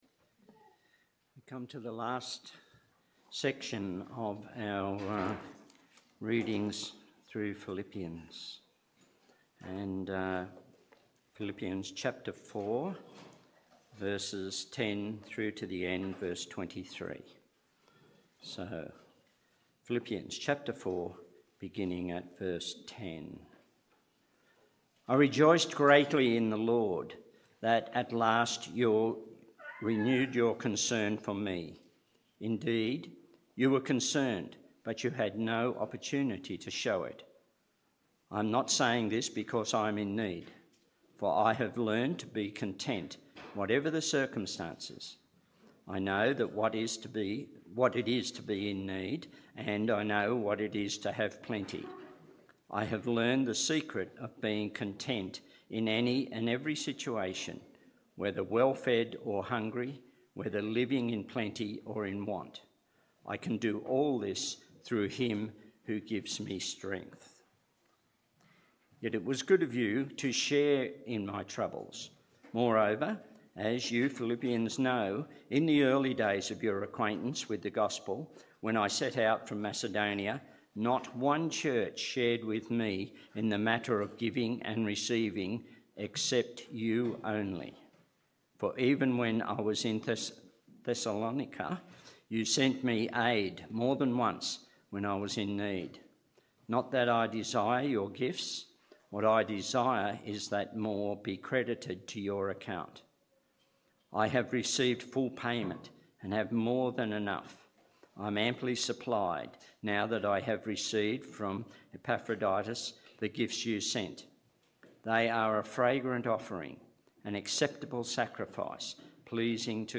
Download Download Reference Philippians 4:10-23 Philippians Current Sermon So long, farewell.